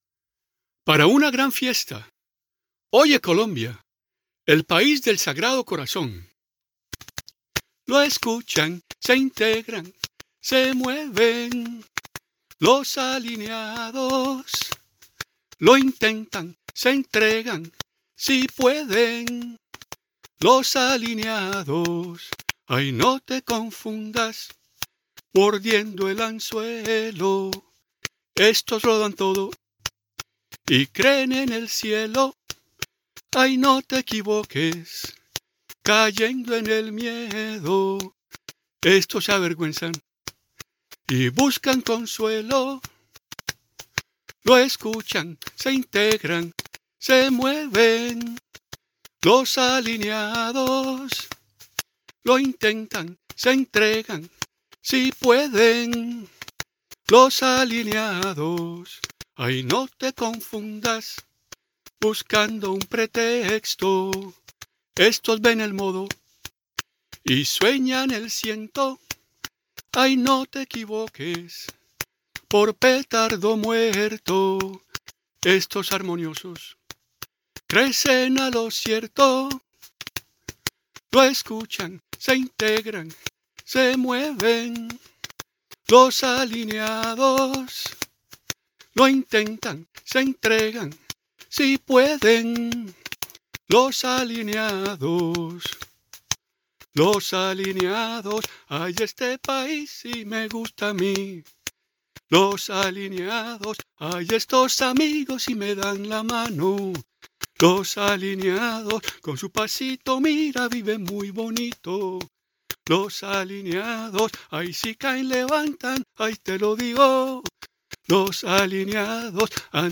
Seguidamente se puede escuchar, a capela, la canción alusiva “Los alineados”.